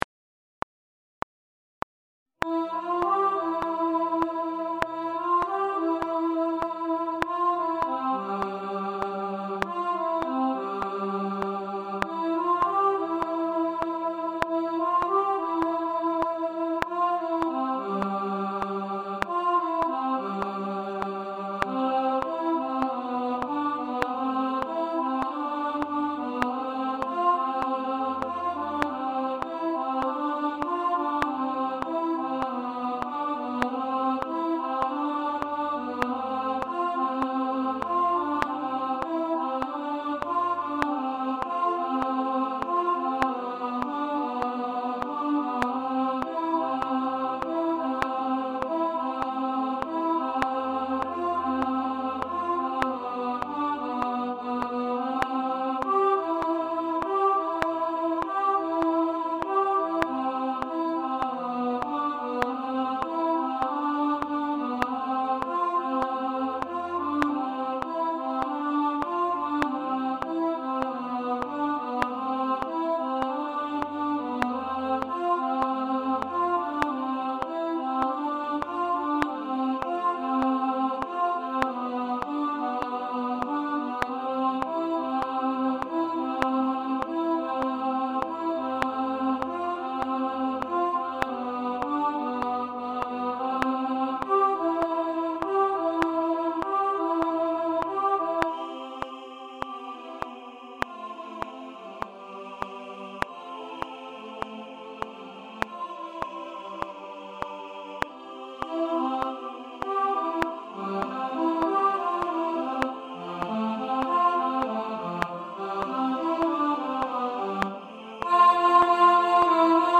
Only You – Alto 2 | Ipswich Hospital Community Choir